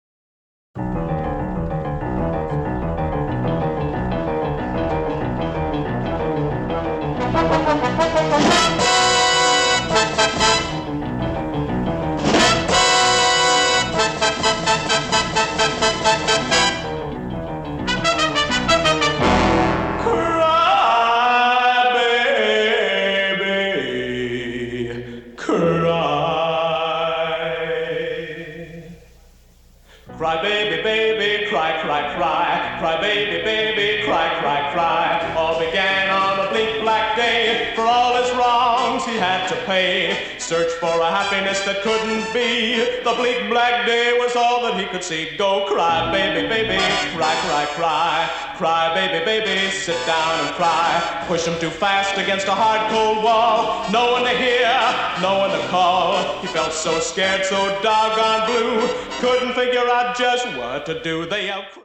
composed for jazz ensembles